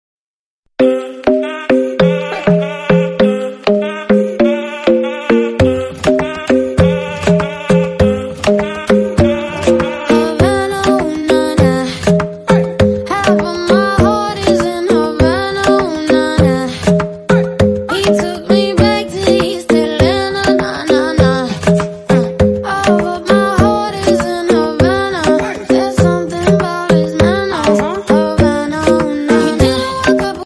Kategorie Marimba Remix